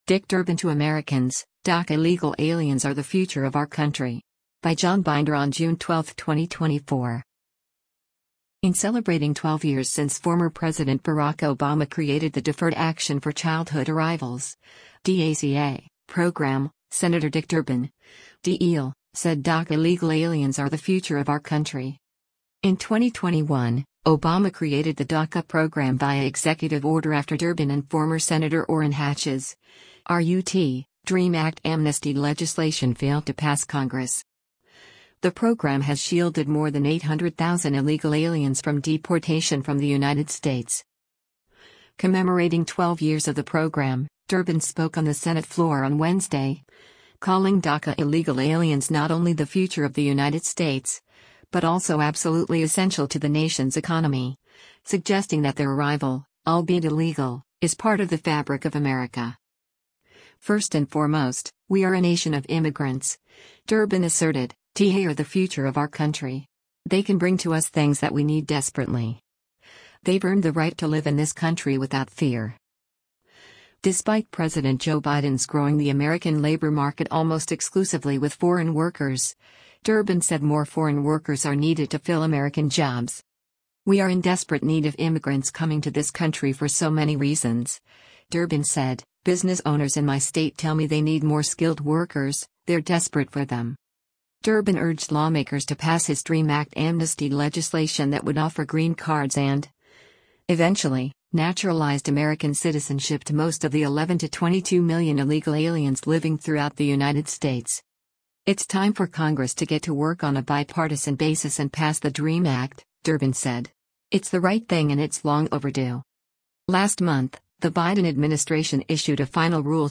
Commemorating 12 years of the program, Durbin spoke on the Senate floor on Wednesday, calling DACA illegal aliens not only “the future” of the United States, but also “absolutely essential” to the nation’s economy, suggesting that their arrival, albeit illegal, is part of the fabric of America.